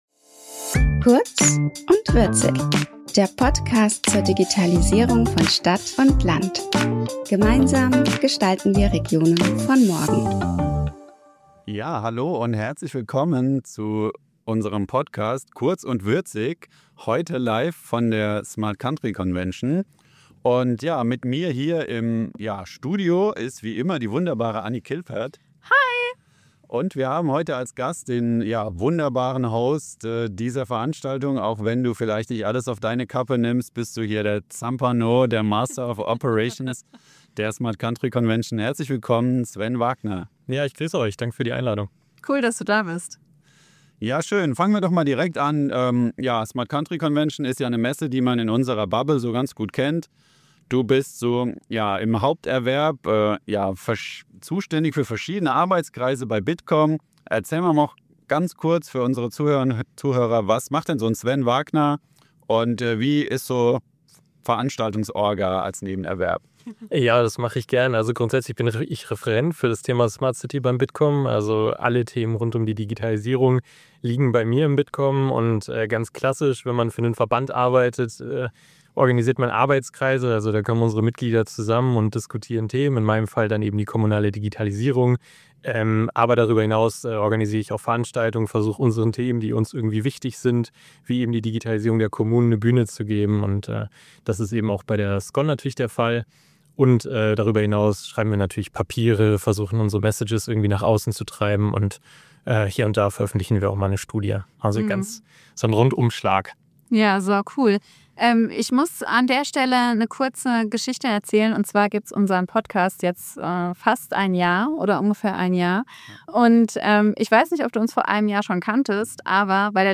Vom Kleiderschrank ins Messe-Studio.